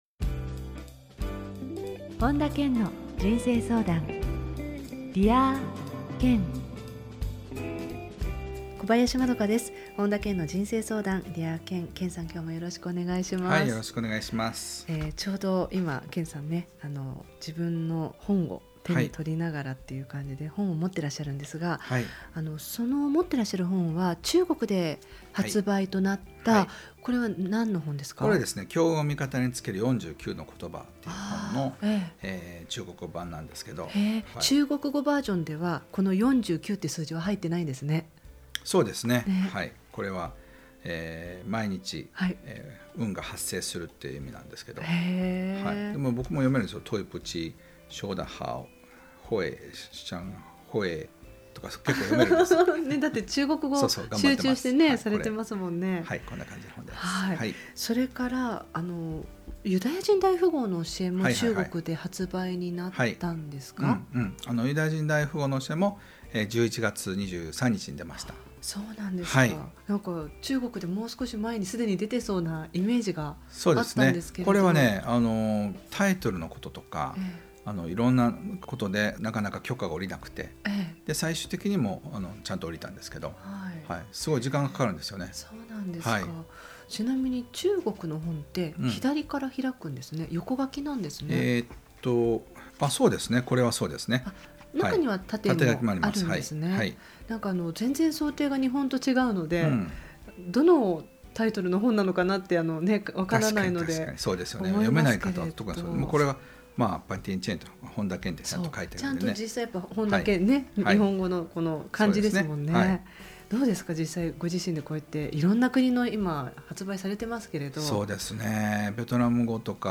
本田健の人生相談 ～Dear Ken～ 傑作選 今回は「不安の乗り越え方」をテーマに、本田健のラジオミニセミナーをお届けします。